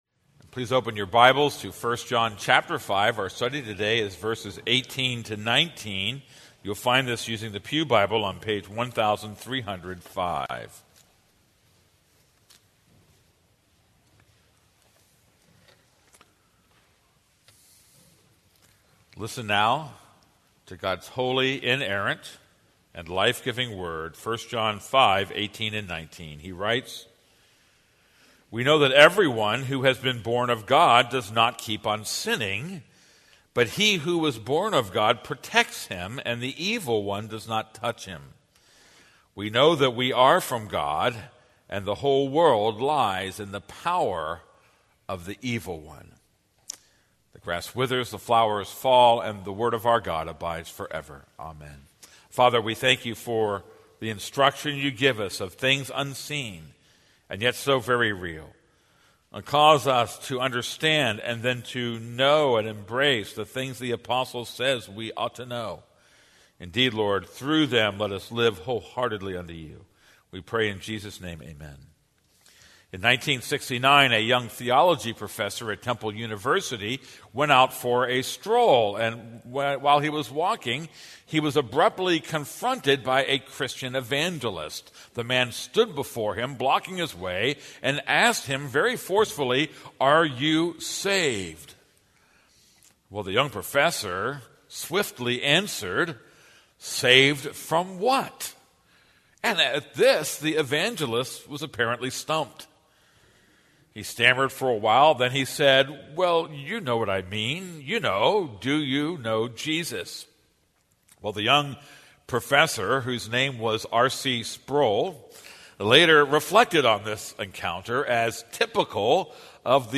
This is a sermon on 1 John 5:18-19.